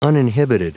Prononciation du mot uninhibited en anglais (fichier audio)
Prononciation du mot : uninhibited